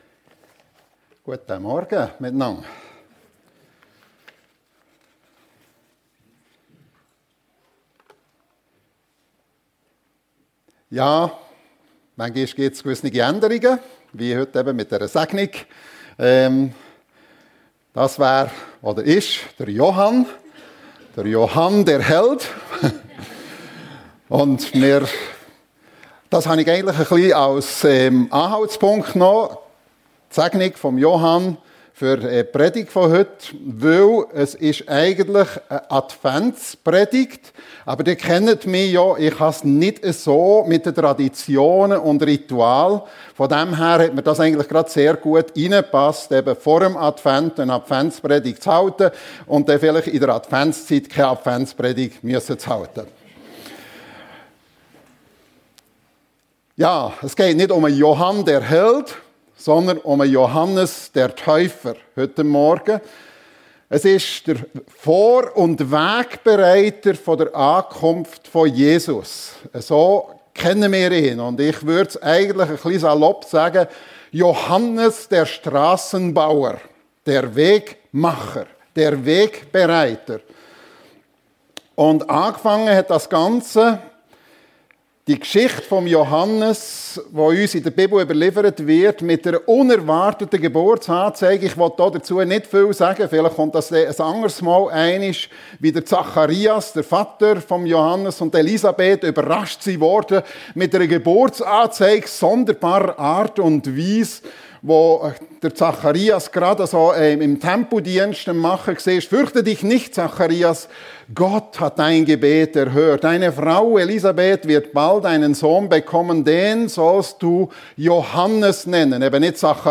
Johannes der Vor- und Wegbereiter für die Ankunft Jesu ~ FEG Sumiswald - Predigten Podcast